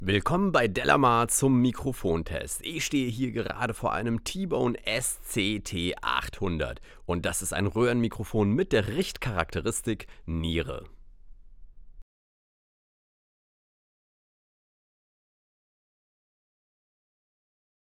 Tatsächlich baut der Klang auf einem beträchtlichen Tieftonfundament auf; kräftige Stimmen mit hohem Bassanteil kommen gut zur Geltung.
Sibilanten erklingen sehr gutmütig.
Das t.bone SCT 800 verursacht nur ein niedriges Rauschen, das sich in der Praxis beim Abmischen sehr selten bemerkbar macht, auch wenn Du mehrere Aufnahmen dieses Mikrofons in einem Mix übereinanderlegst.
Klangbeispiele vom t.bone SCT 800
Sprache männlich
t.bone_sct_800_testbericht_speech_m.mp3